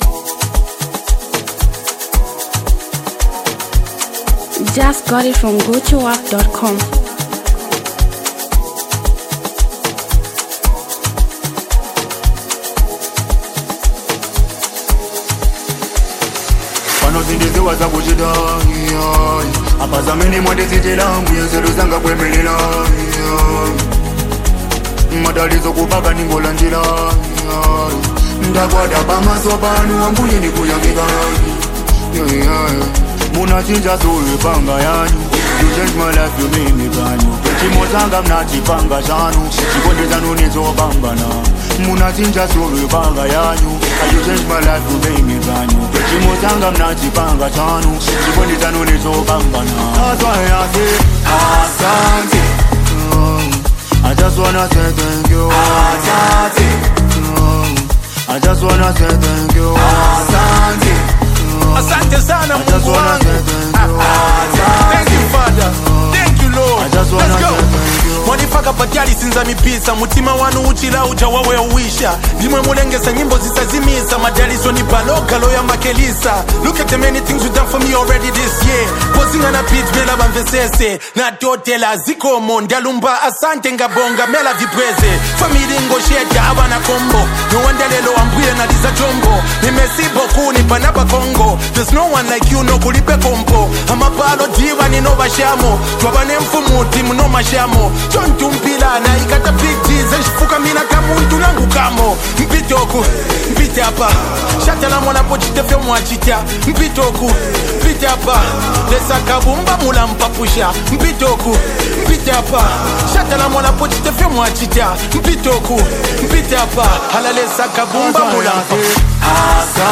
Zambian Mp3 Music
Powerful Hip-hop Sound